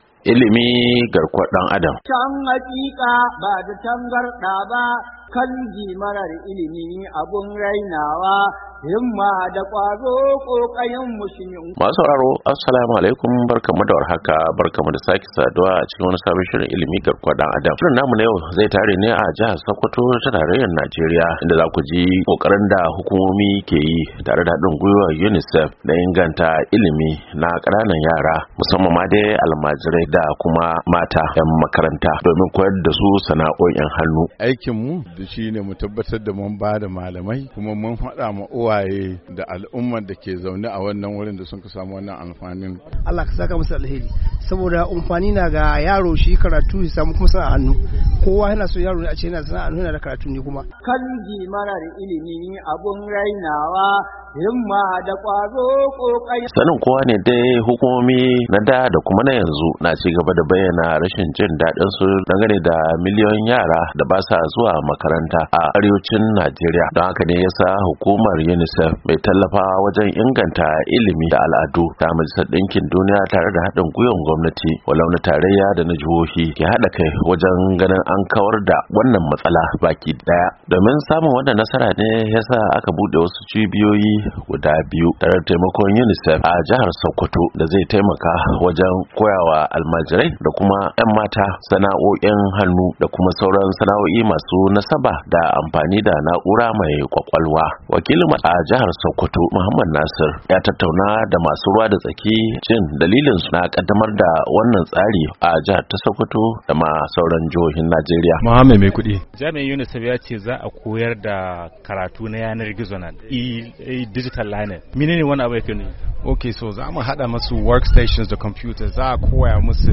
A shirin Ilmi na wannan makon mun tattauna ne da wani wakilin hukumar UNICEF akan batun samar da cibiyoyi guda biyu a Sokoto domin sanya Almajiri da yara mata masu tasowa koyon Dijital da sauran fasahohin sana'o'i a wani mataki na rage adadin miliyoyin yaran da ba sa zuwa makaranta.